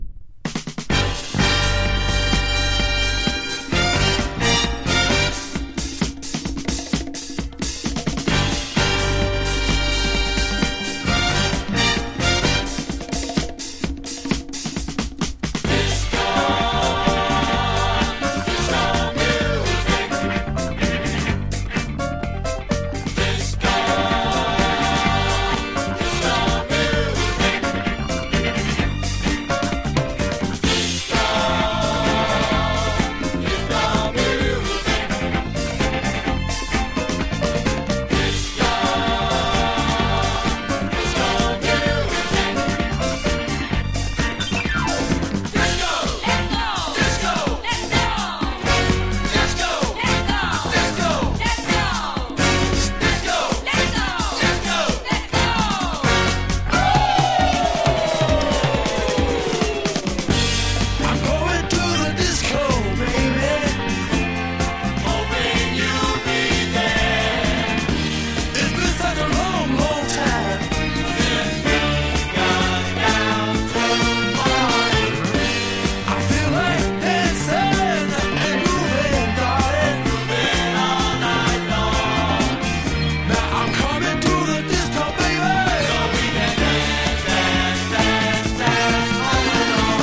とにかく飛び抜けてグルーヴィーでアッパーなディスコ・サウンドが最高です!!呆れるほどアッパーです。
FUNK / DEEP FUNK# DISCO